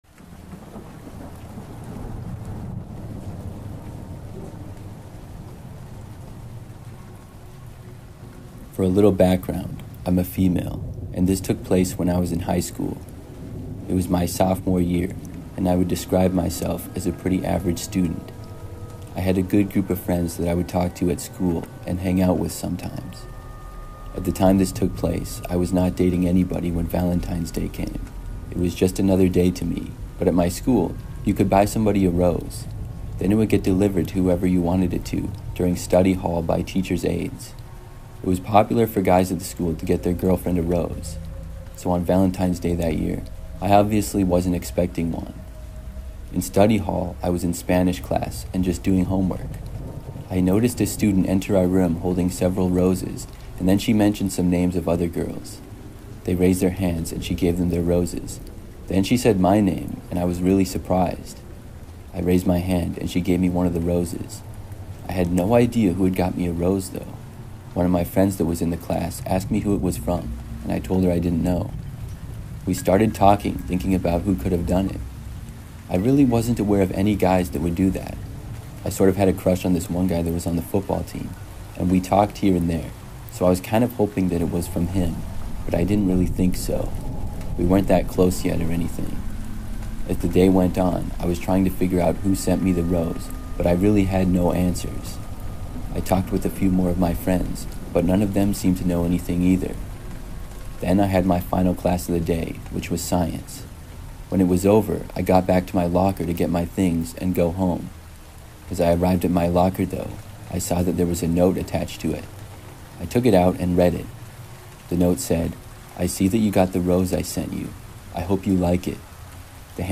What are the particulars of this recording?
Before the story begins, Scary Stories wants you to know something important: all advertisements are placed at the very beginning of each episode so nothing interrupts the experience once the darkness settles in.